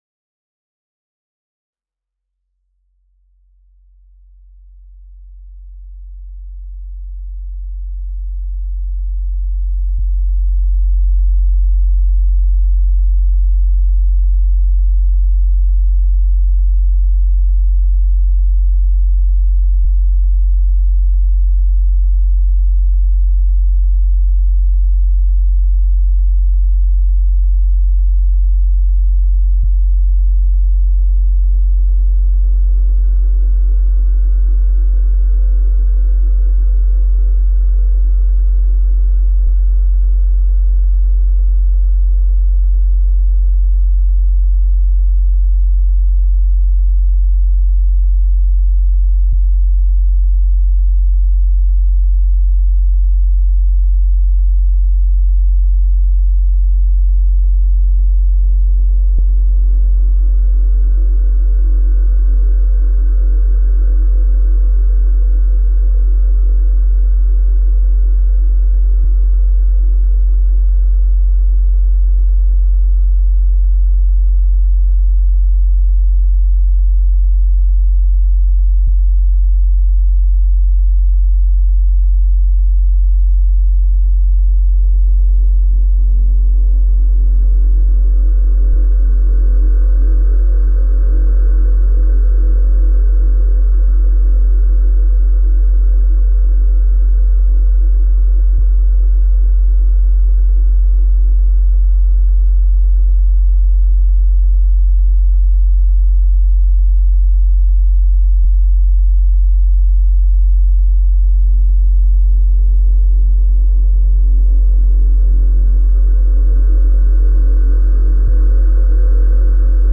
Чистая синусоидальная волна 45 Гц с модуляцией
Гармонические обертоны, кратные основной частоте
Объемное панорамирование с эффектом "расширения"
Эфирные синтезаторные тембры с плавающей тональностью